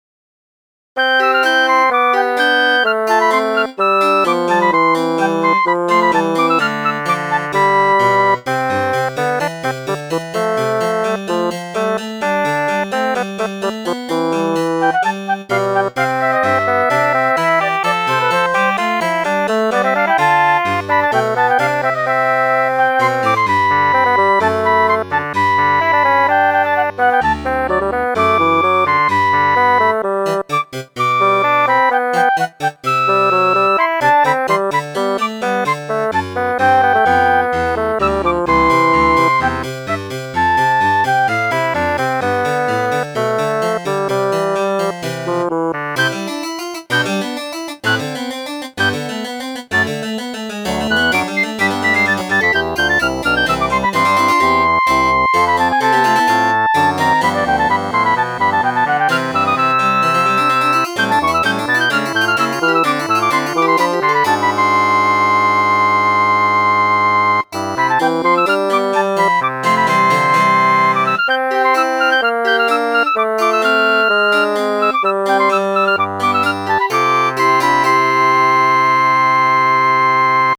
(2008년 8월 16일) 하프시코드, 플루트, 바순 삼중주